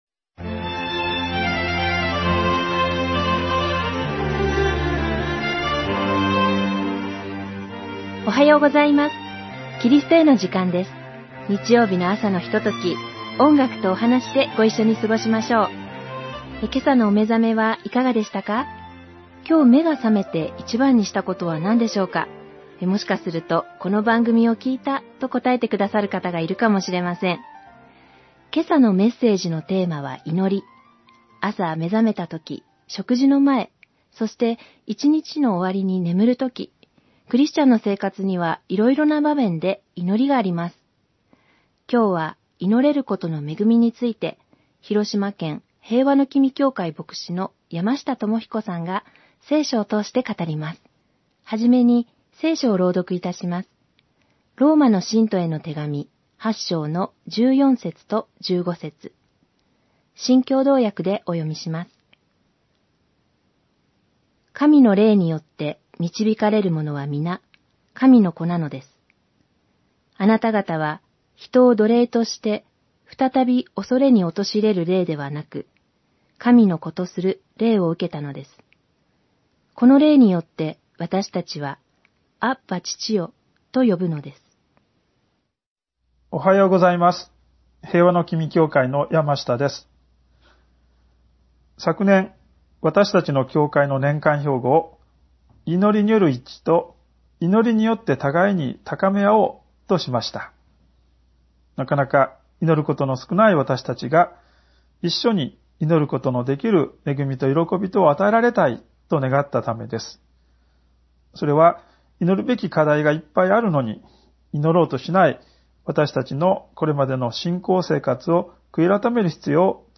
※ホームページでは音楽著作権の関係上、一部をカットして放送しています。